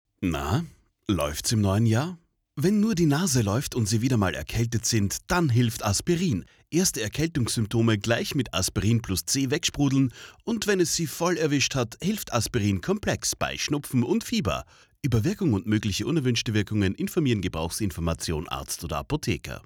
Bayer Austria, Aspirin, Audiospot